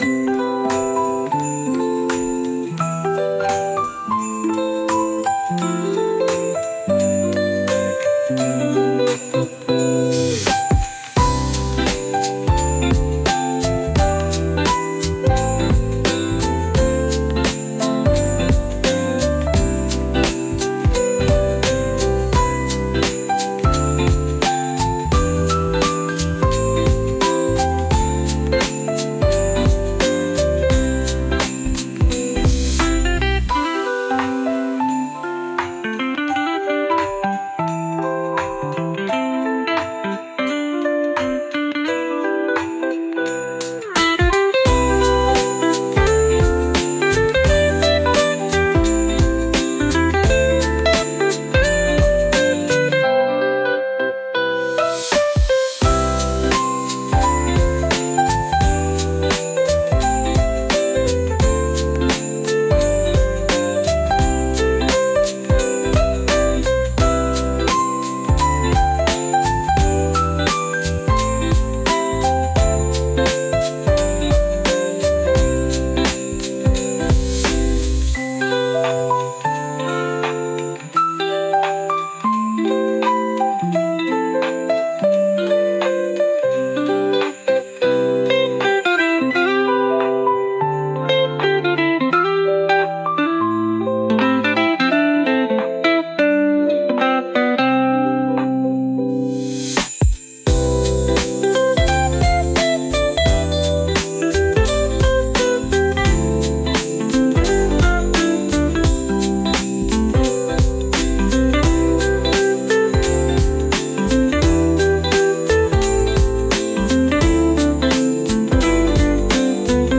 かわいい ピアノ